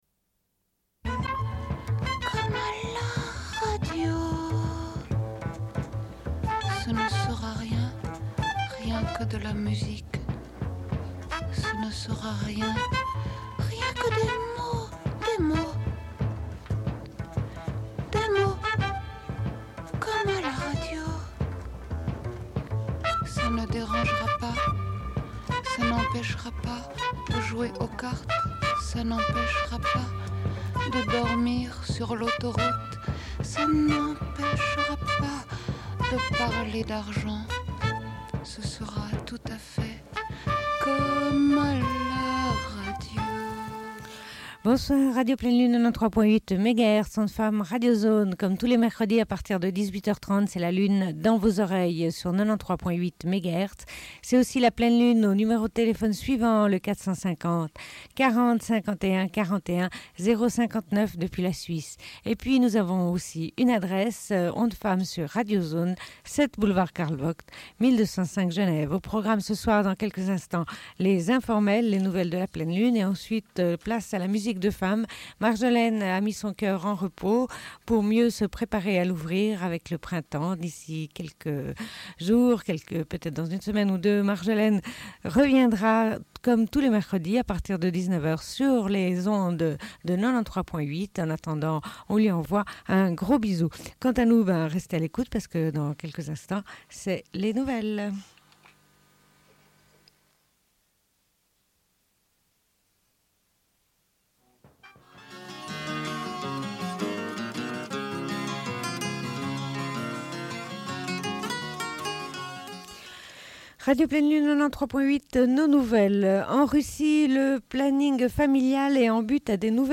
Bulletin d'information de Radio Pleine Lune du 11.02.1998 - Archives contestataires
Une cassette audio, face B